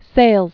(sālz)